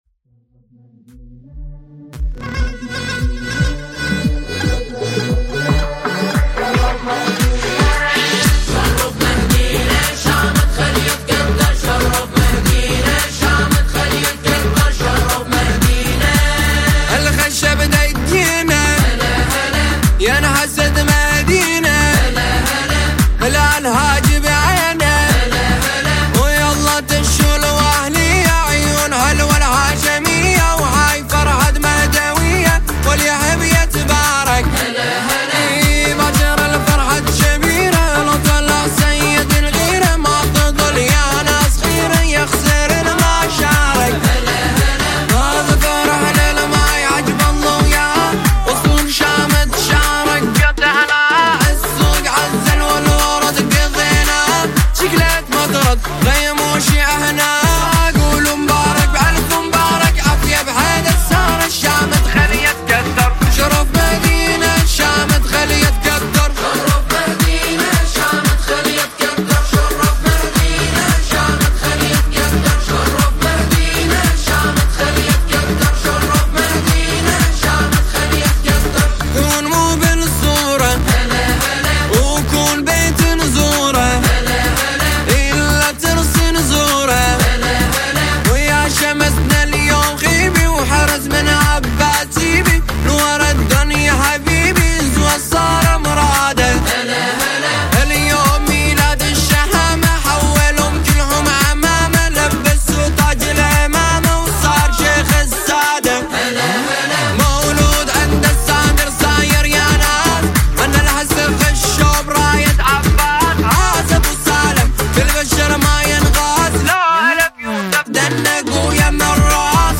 ژانر: سرود مناسبتی